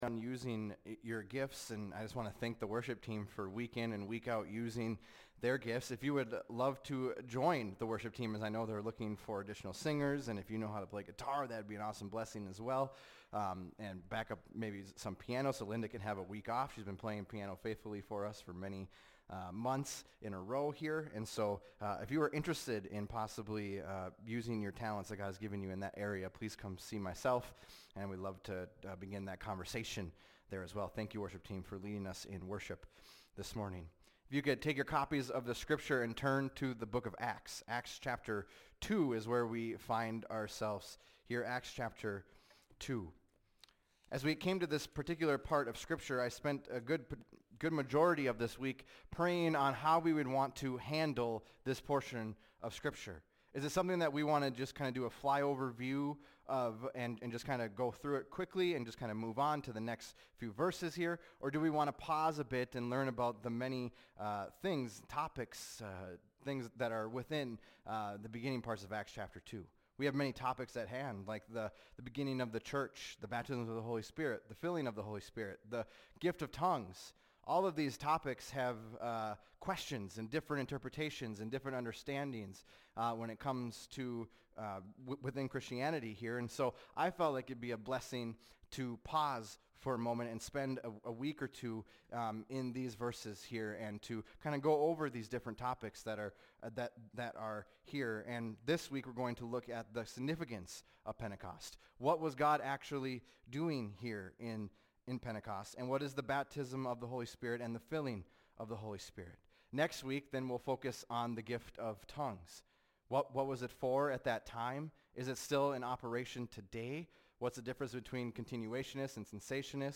fbc_sermon_021625.mp3